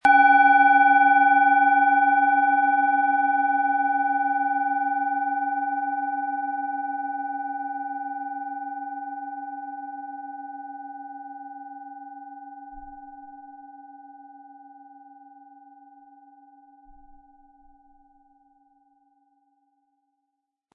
Planetenton 1
Von Hand getriebene Klangschale mit dem Planetenklang Merkur aus einer kleinen traditionellen Manufaktur.
SchalenformBihar
MaterialBronze